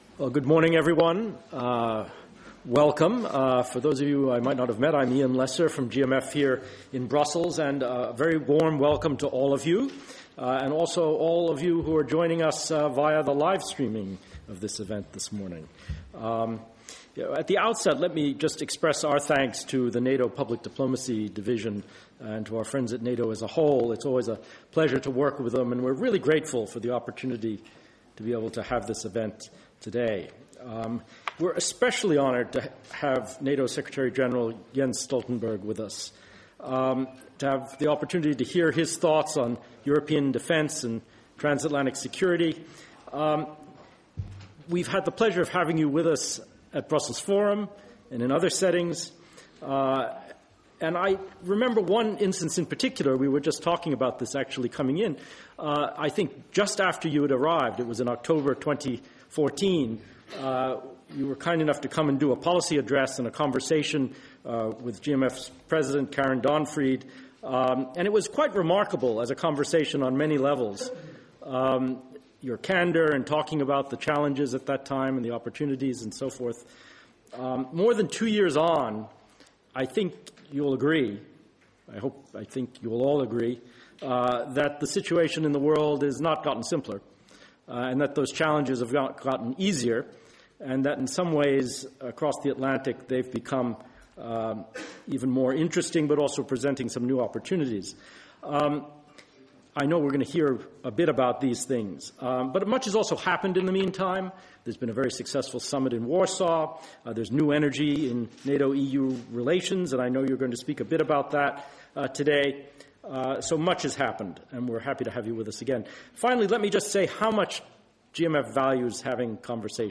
Speech by NATO Secretary General Jens Stoltenberg at an event hosted by the German Marshall Fund of the United States (GMF)